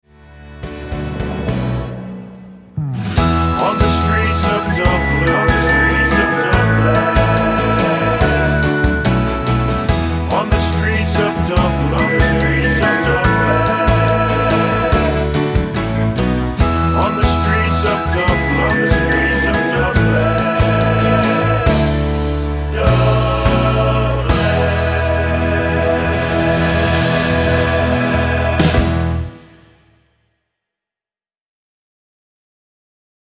--> MP3 Demo abspielen...
Tonart:E mit Chor